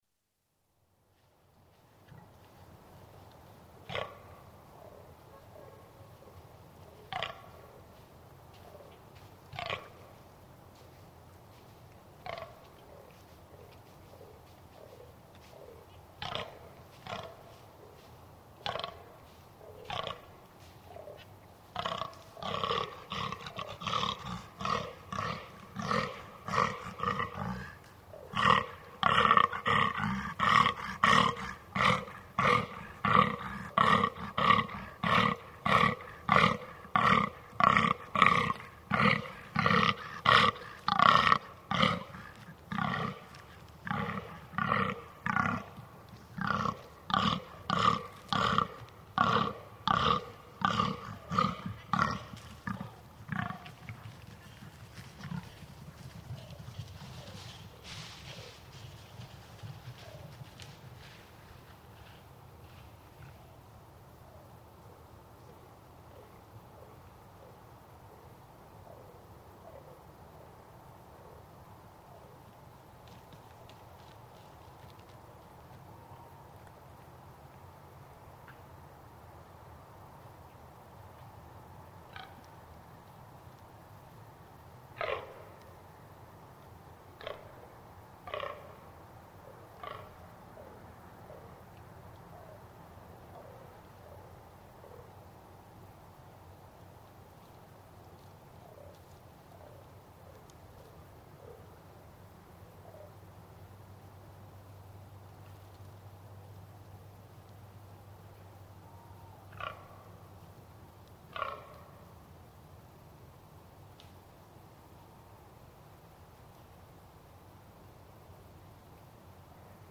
Glen einich, Inverness, Scotland. Recording on a heathland with trees and bracken on a foggy, dark and very calm autumn evening. Fallow Deer are rutting: the pig-like grunts are from the bucks, the quiet bleats are the does. In the far distance, a Tawny Owl hoots.